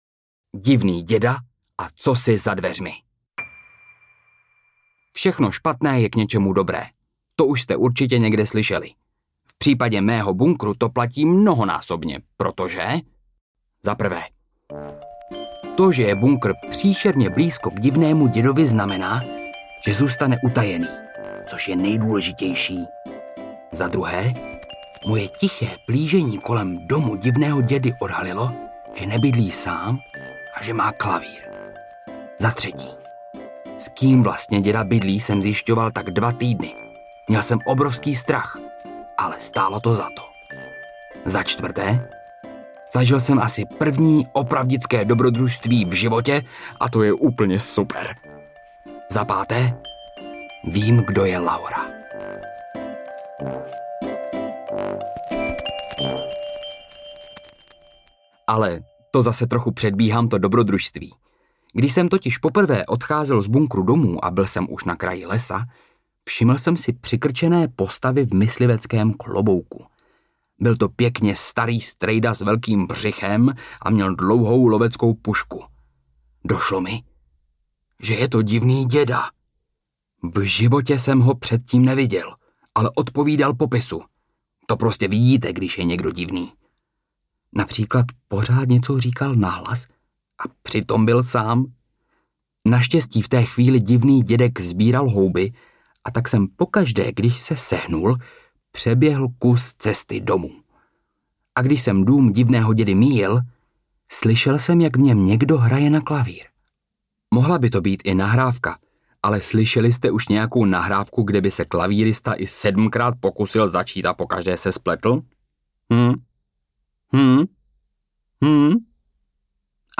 Tři kámoši a fakticky fantastický bunkr audiokniha
Ukázka z knihy